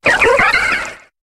Cri de Keldeo Aspect Normal dans Pokémon HOME.